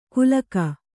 ♪ kulaka